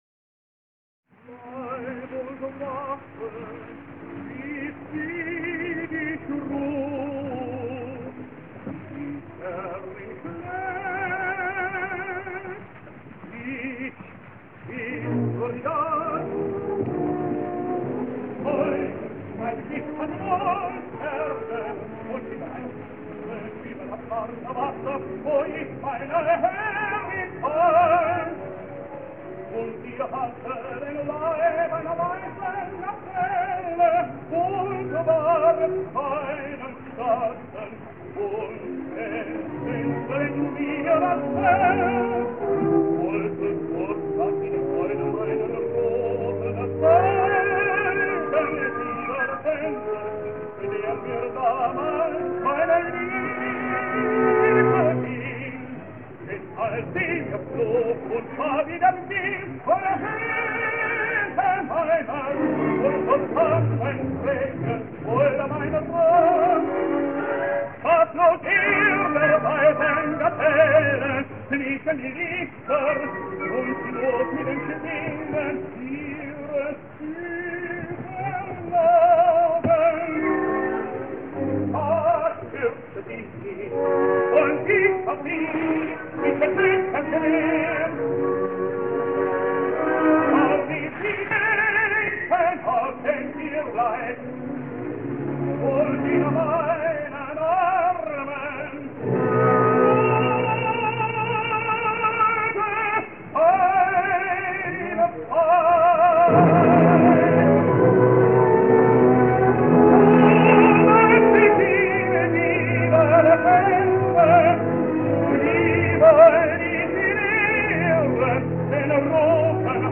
“Bleib’ und wache”, atto I, Imperatore (Franz Völker, Vienna, 1933):